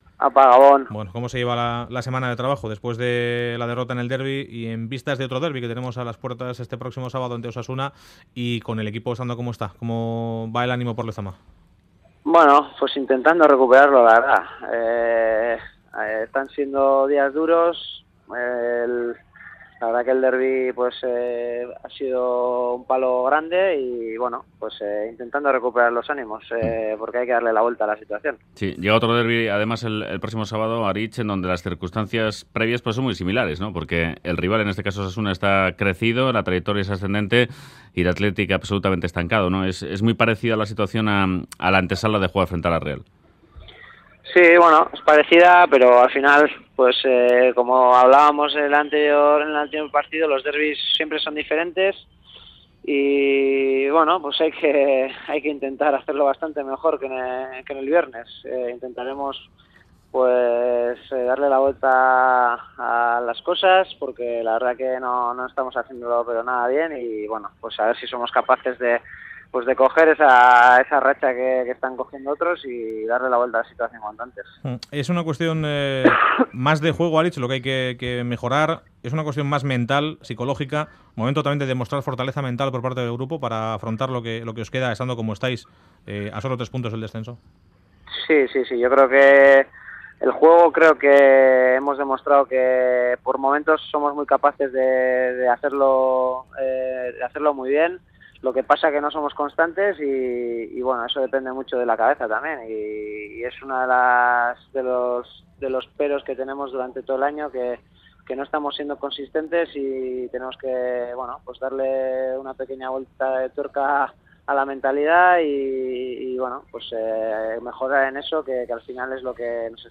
Aritz Aduriz, jugador del Athletic | Entrevista en Radio Euskadi